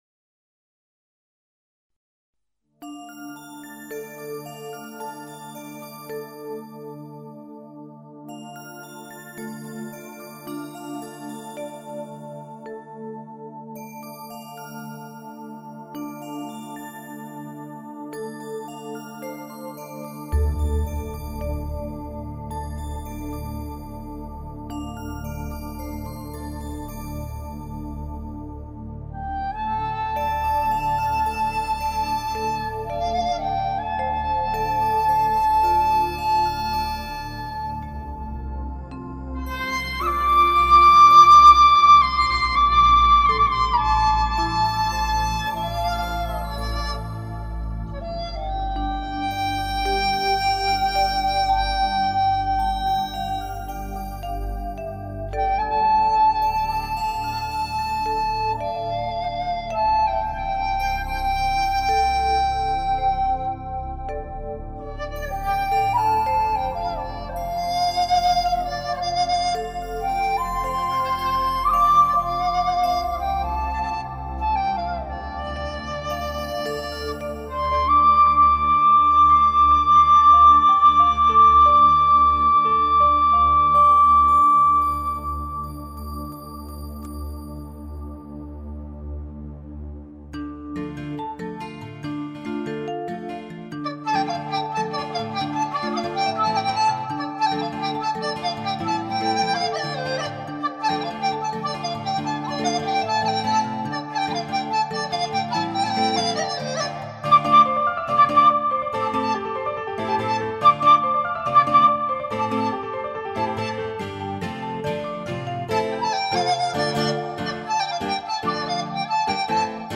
【高山笛乐】《山寨孩子的梦》